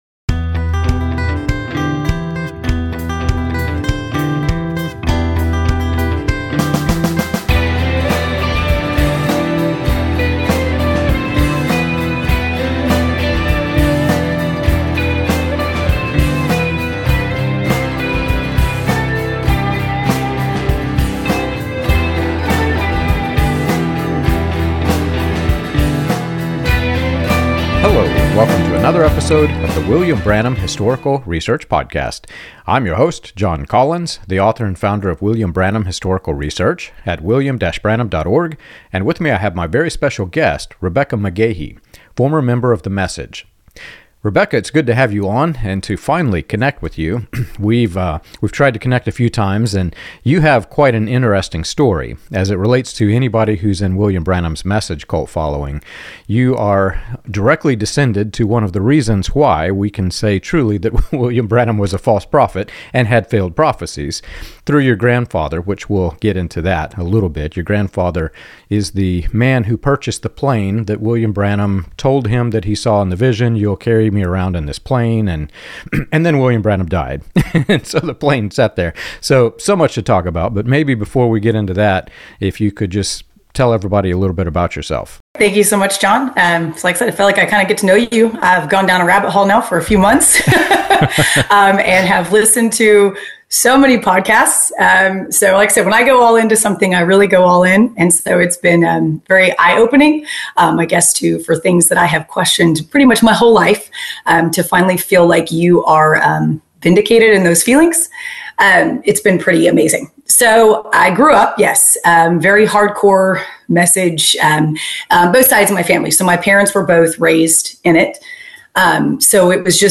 The conversation offers hope for those questioning high-control religious systems, showing how reading the Bible directly, asking hard questions, and encountering grace outside the Message can lead to genuine spiritual healing and freedom.